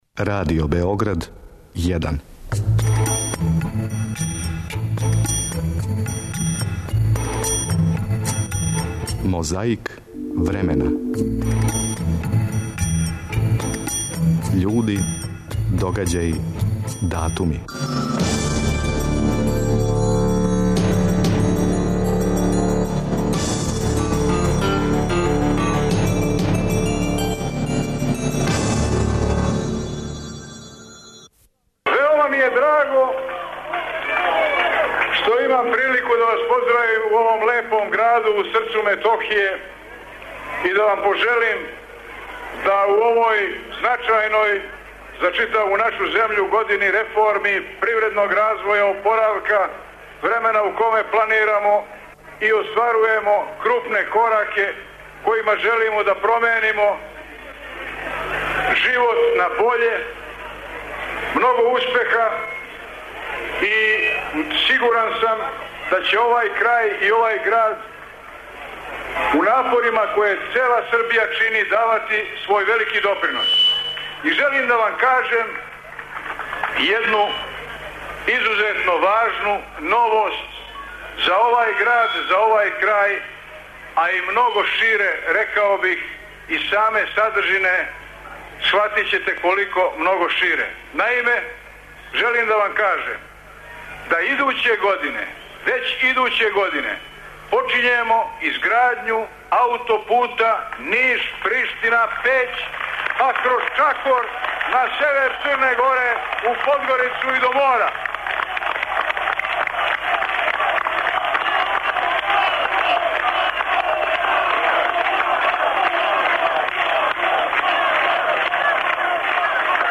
Звучна коцкица ће нас подсетити како је било када је тога дана 1991. године, на седници Сабора, Фрањо Туђман прогласио независност Хрватске.
Звучна коцкица преноси и то.